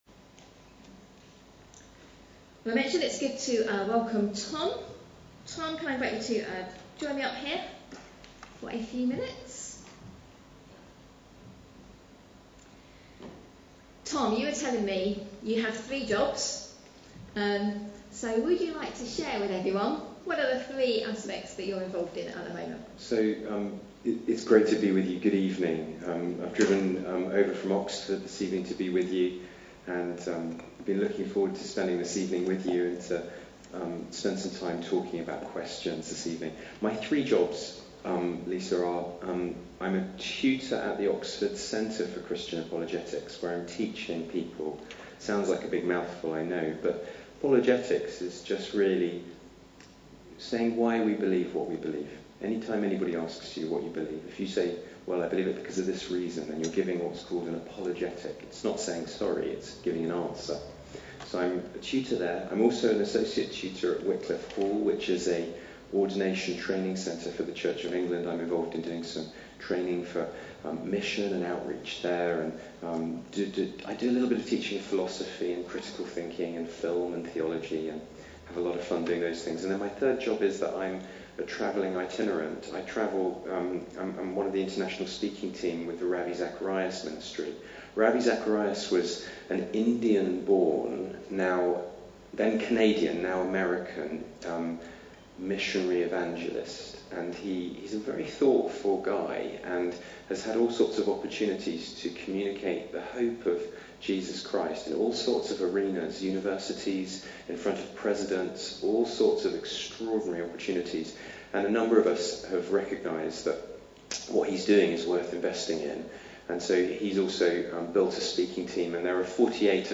A sermon preached on 8th November, 2015.